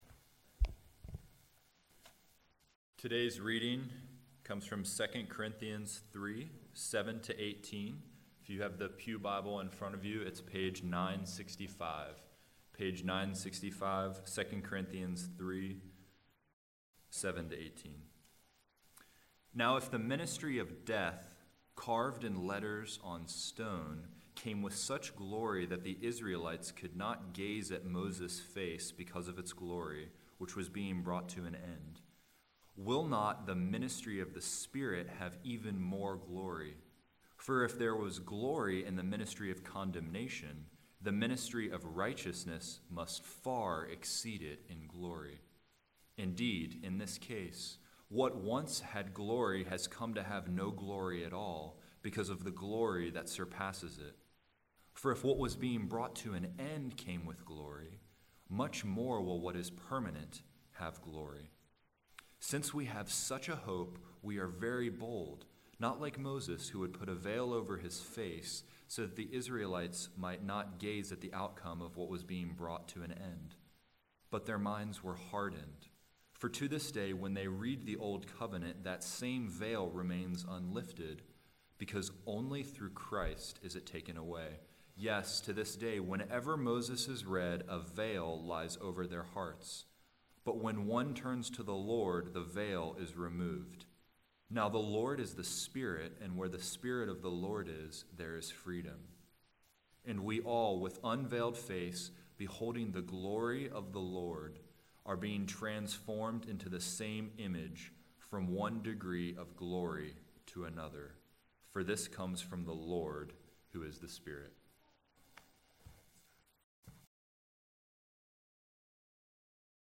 Posted by VSBC Blog Administrator The worship service at Vine Street Baptist Church included scripture, songs, fellowship, and the preaching of God’s Word. Psalm 150 Leaning on the Everlasting Arms Oh, How I Love Jesus Fellowship & Announcements Prayer & Offering Tis So Sweet to Trust in Jesus How Great Thou Art Today’s message was the next in a series through the book of 2nd Corinthians.